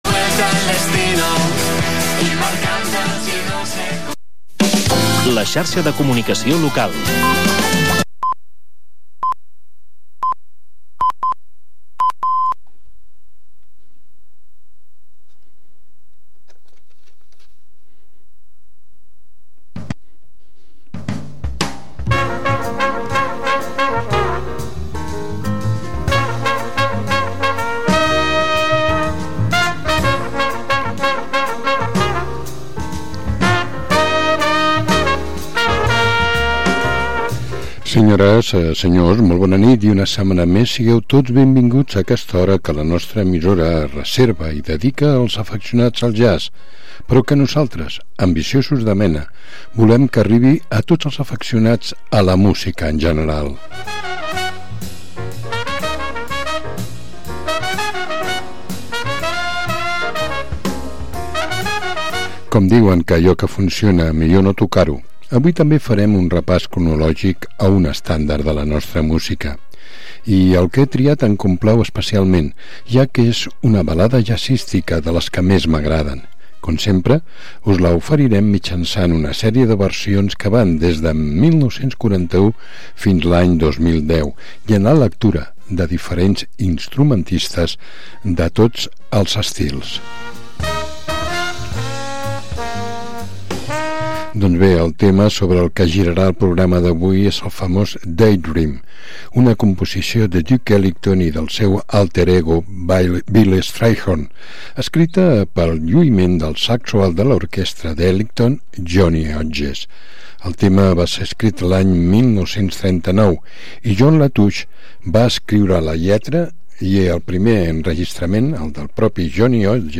Jazz Setmanal